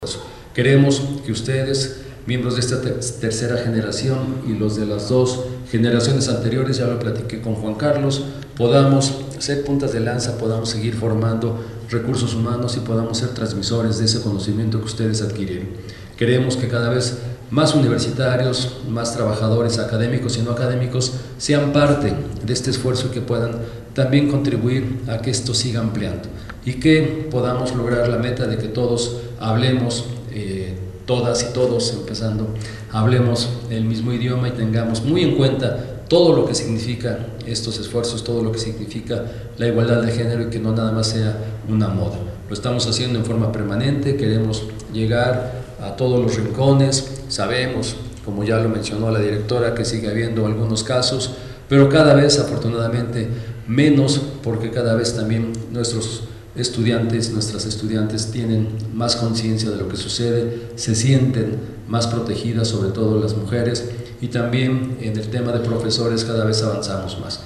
Entrega constancias a personal administrativo y docente que tomó el Diplomado en Género e Igualdad en la BUAP En el marco de la entrega de constancias a 25 académicos y administrativos que concluyeron con éxito el Diplomado en Género e Igualdad en la BUAP, el Rector Alfonso Esparza Ortiz destacó el compromiso que mantiene la Universidad con la cultura de equidad, de ahí el impulso que se da a foros, talleres y diplomados, pero sobre todo a la creación de un protocolo de género que se presentará